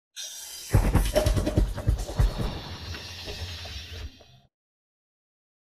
Это, видимо, какой-то барабанный луп из библиотеки до 2001 года выпуска (трек, откуда срезано, 2001 года). Я именно про ударные, не про начало и концовку.